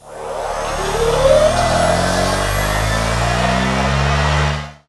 Power_on.ogg